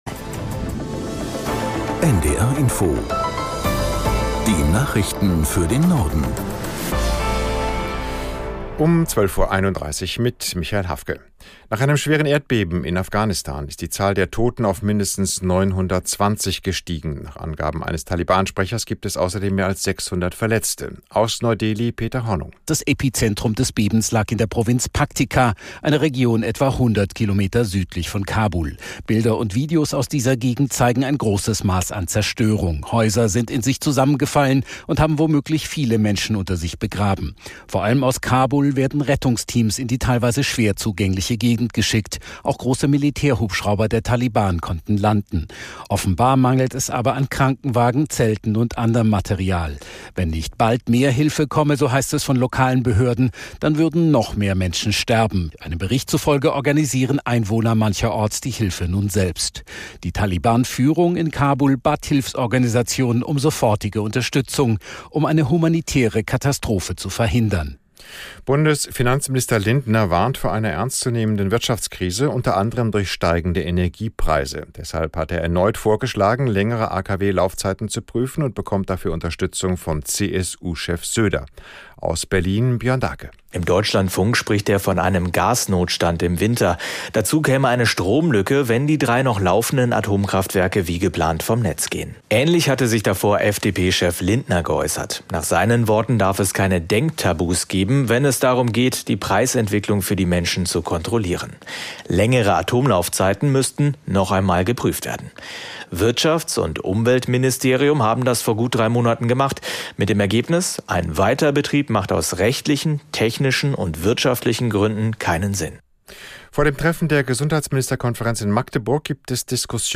Nachrichten - 15.07.2022